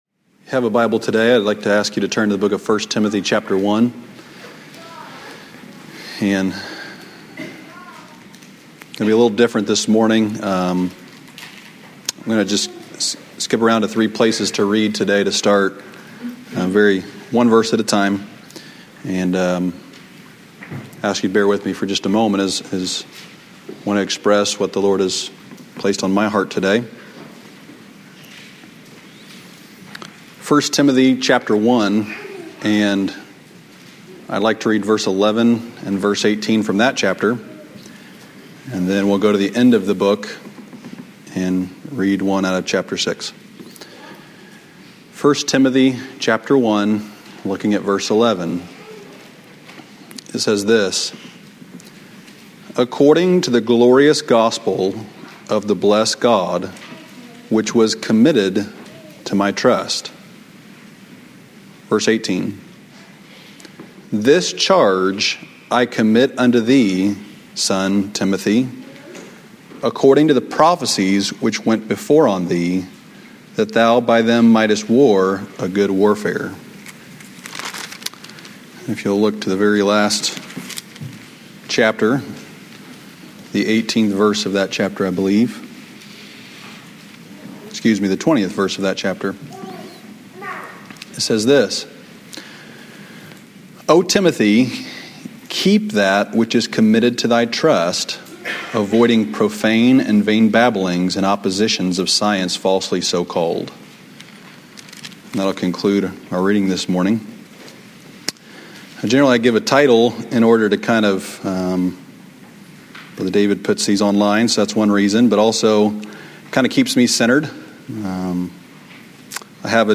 "Drive and Go Forward" 2 Kings 4:22-24 Sunday evening revival sermon from July 21, 2024 at Old Union Missionary Baptist Church in Bowling Green, Kentucky.